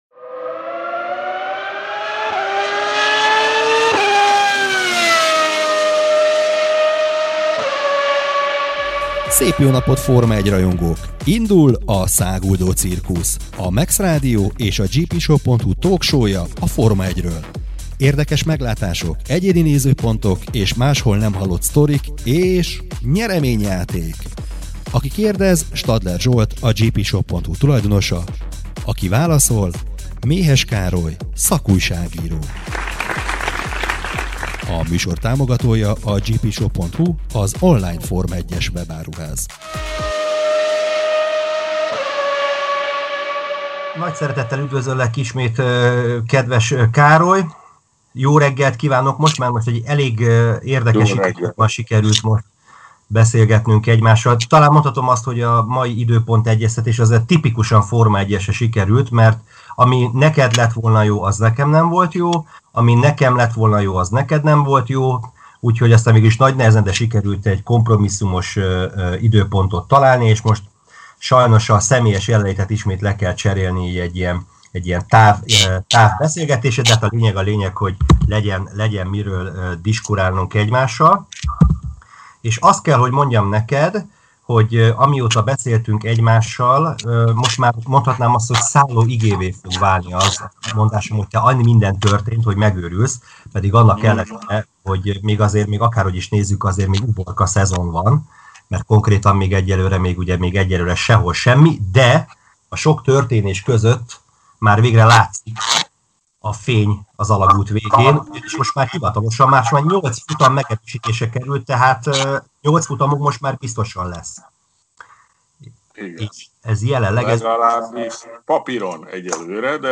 Forma 1-es talk shownk 2020 június 11-i adásának a felvétele.